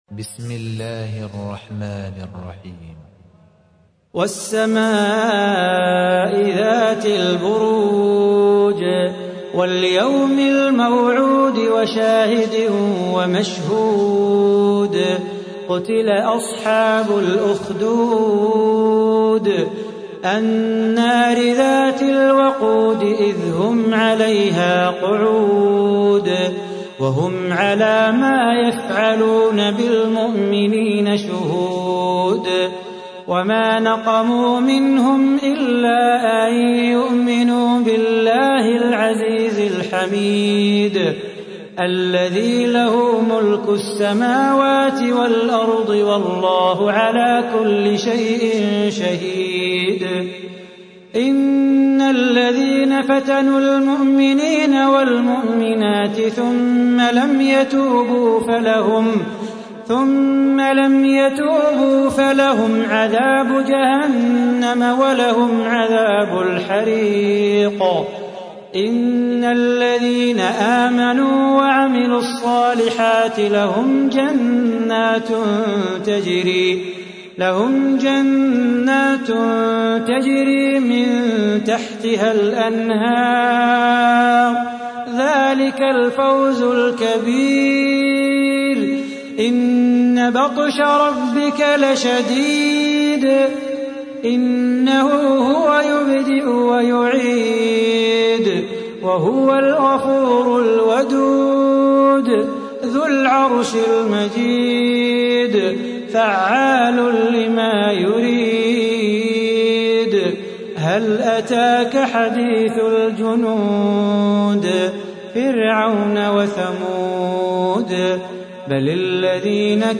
تحميل : 85. سورة البروج / القارئ صلاح بو خاطر / القرآن الكريم / موقع يا حسين